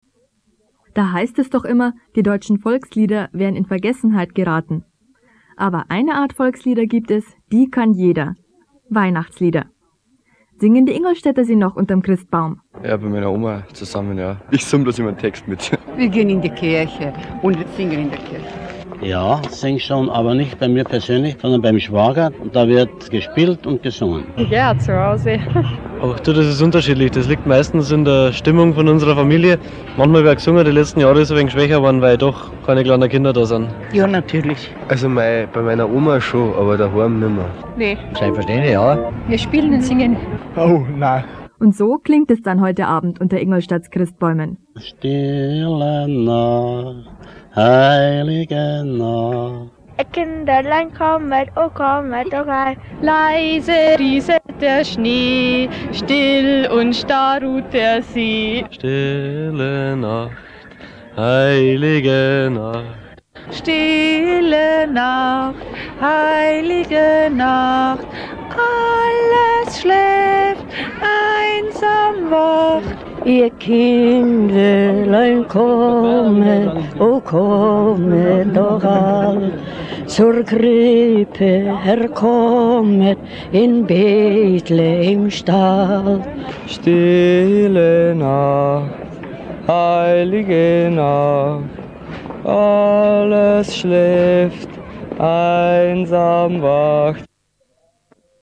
Auf dem Stück bis ins Büro fiel mir die weihnachtlichste meiner Straßenumfragen ein: Wie ich Weihnachten 1987 Ingolstädter*innen dazu brachte, mir Weihnachslieder ins Mikrophon zu singen.
2-08-Weihnachtslieder.mp3